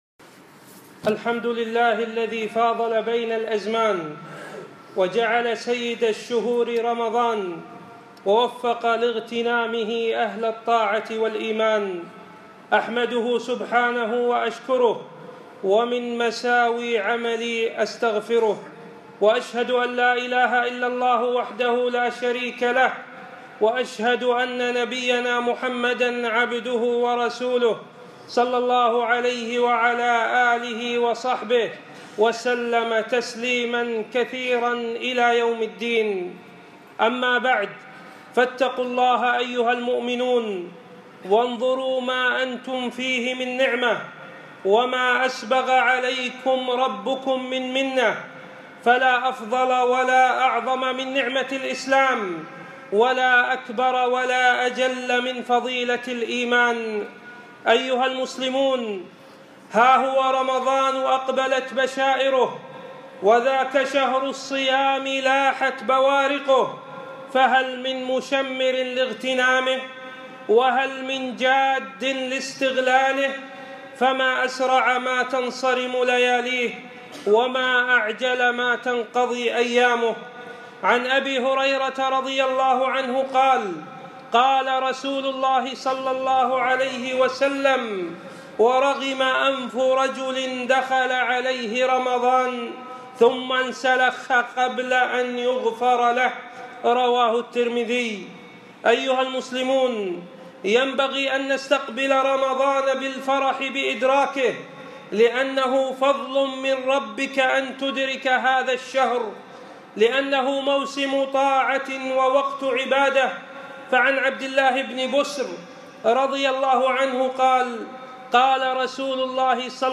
خطبة - إستقبال رمضان